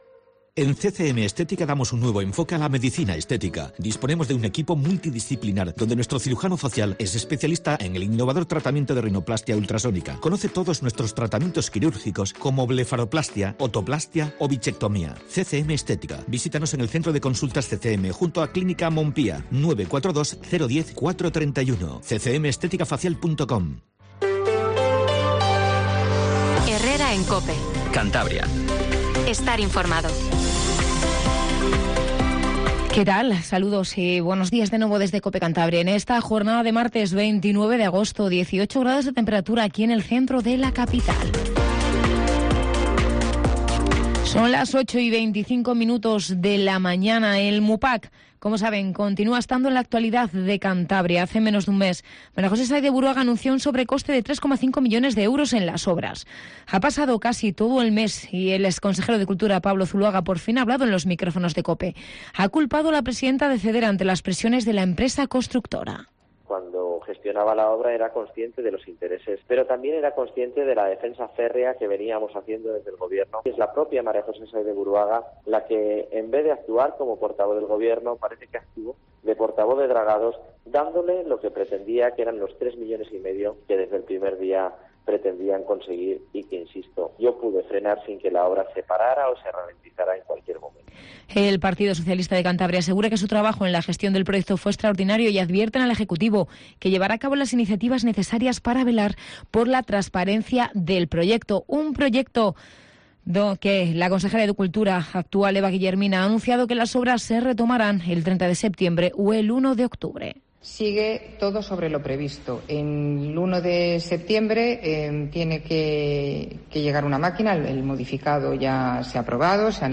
Informativo Matinal Cope 08:20